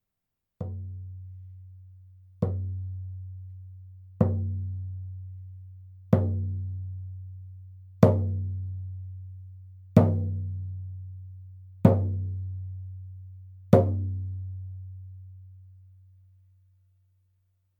フレームドラム　ネイティブアメリカン（インディアン）スタイル
素材：牛革・天然木
パキスタン製フレームドラム 音